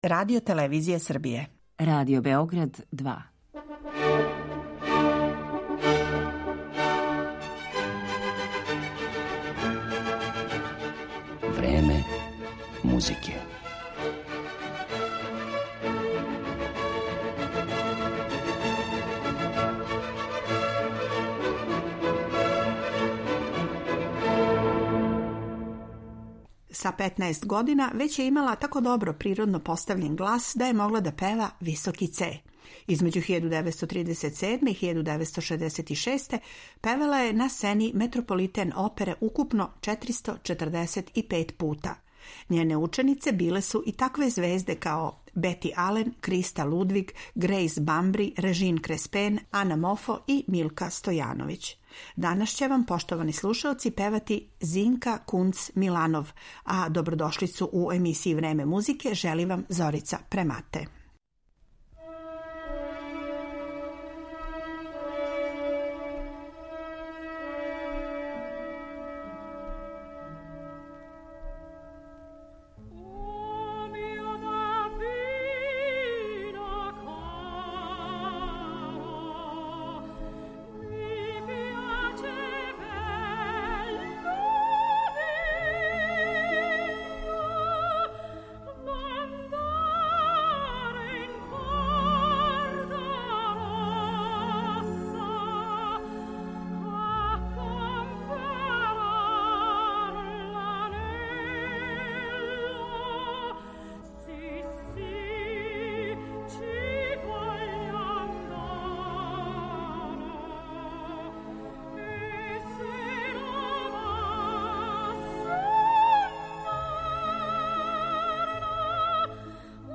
Слушаћете и избор архивских снимака на којима је ова уметница светског реномеа певала арије из опера Вердија и Пучинија са којима се, средином прошлог века, прославила на сцени Метрополитена.